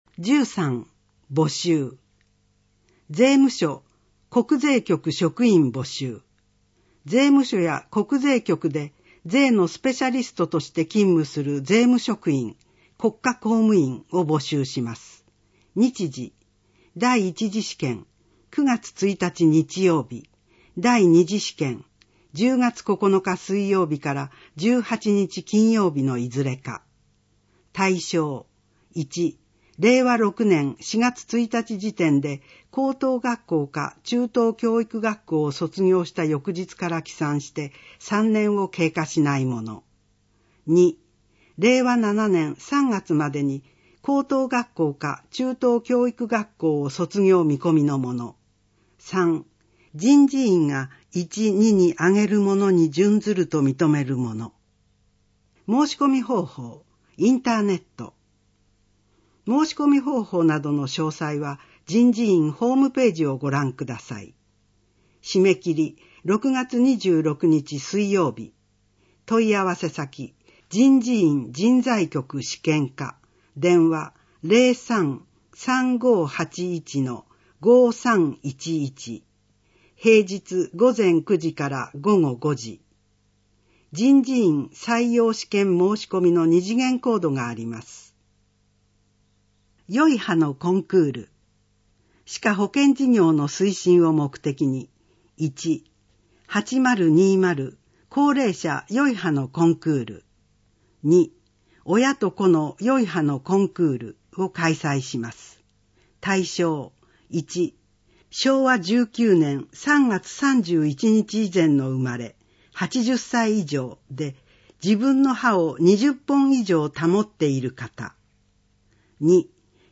取手市の市報「広報とりで」2024年6月15日号の内容を音声で聞くことができます。音声データは市内のボランティア団体、取手朗読奉仕会「ぶんぶん」の皆さんのご協力により作成しています。